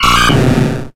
Grito de Totodile.ogg
Grito_de_Totodile.ogg